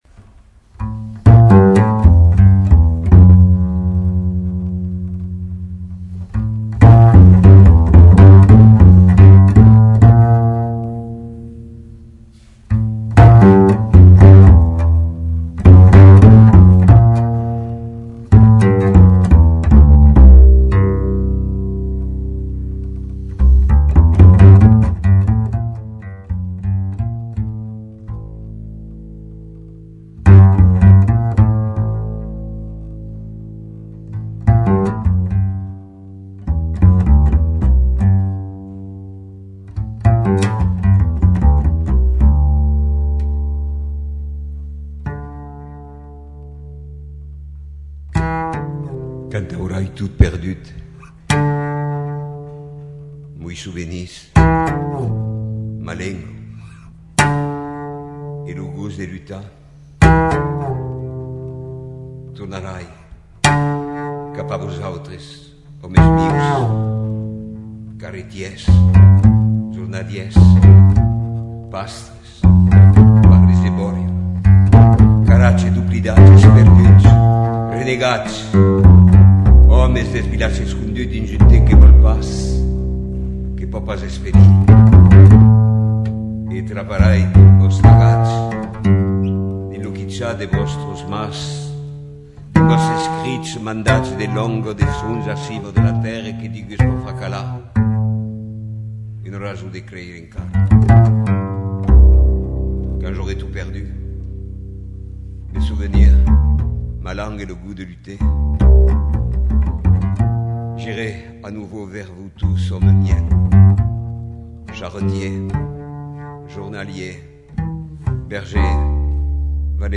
Le Chant des millénaires : soirée autour du poète
accompagné à la contrebasse